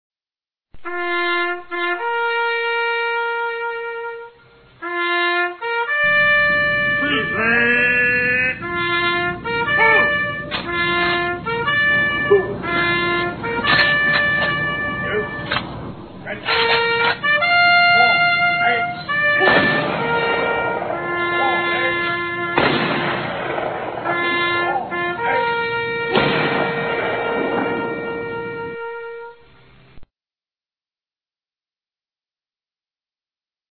Tags: sound fx quotes drum rolls